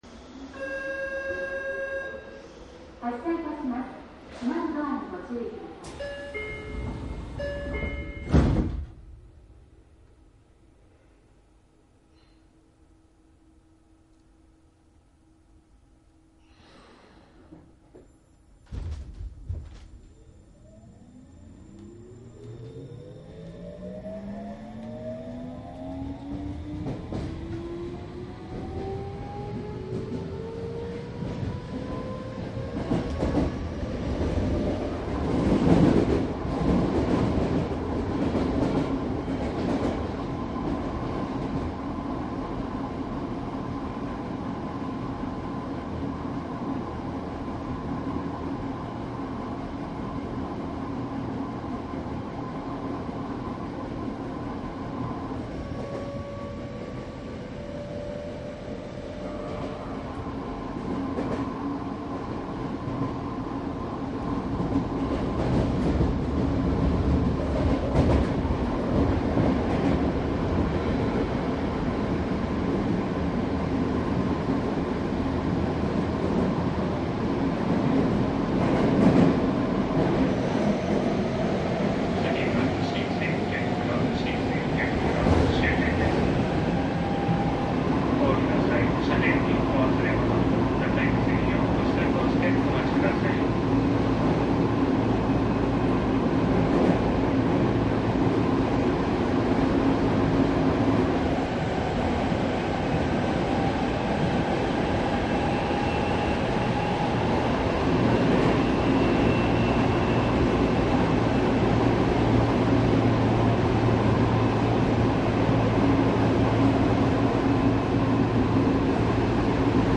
西武有楽町線 07系 走行音CD
いずれもマイクECM959です。DATかMDの通常SPモードで録音。
実際に乗客が居る車内で録音しています。貸切ではありませんので乗客の会話やが全くないわけではありません。